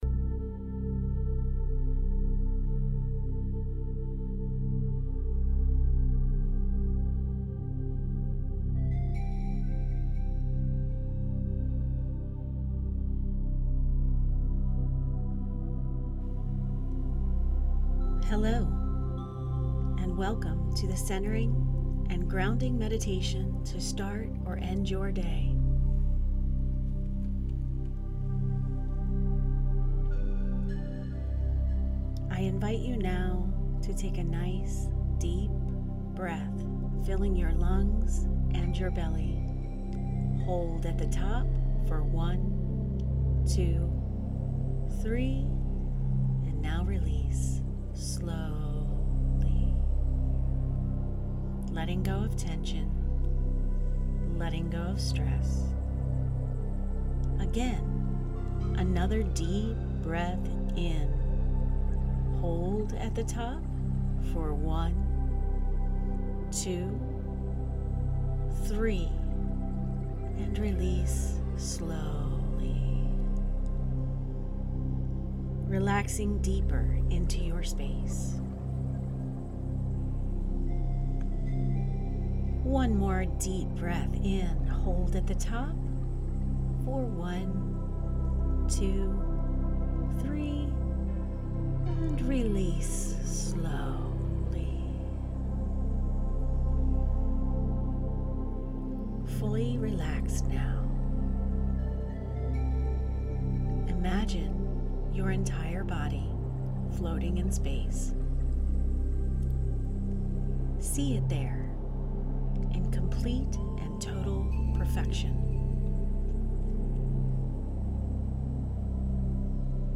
Using hypnotic language, this meditation guides you into deep subconscious shifts.
CenteringGroundingMeditation.mp3